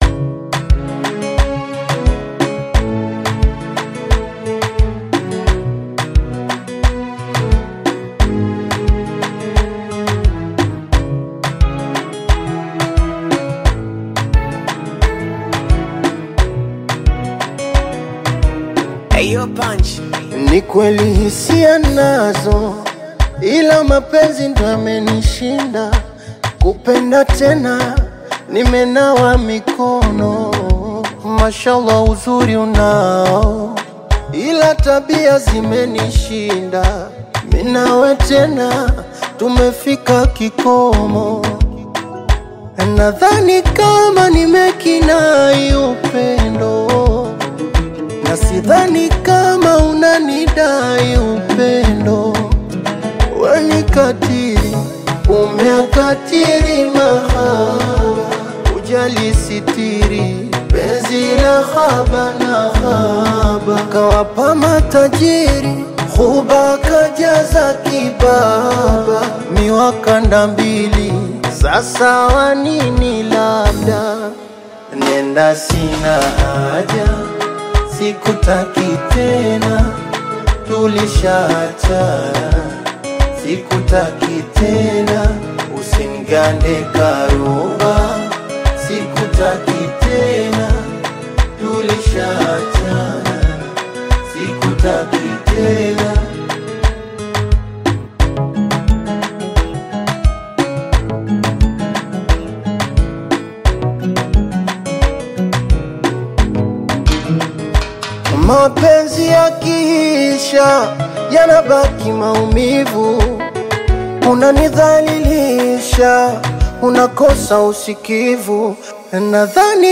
soft melodies